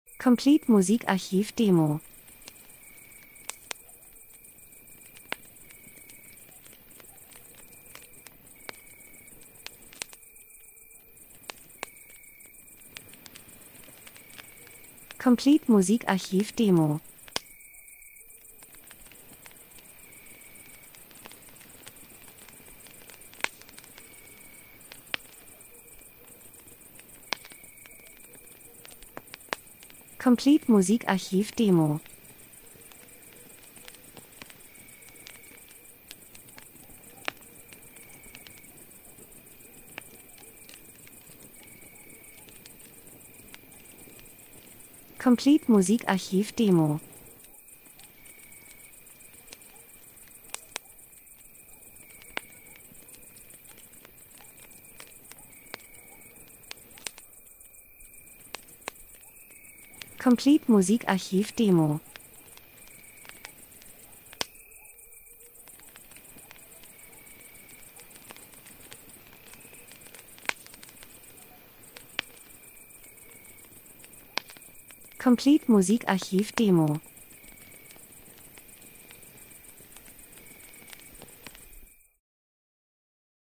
Sommer -Geräusche Soundeffekt Natur Abend Lagerfeuer 01:23